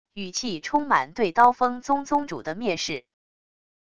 语气充满对刀锋宗宗主的蔑视wav音频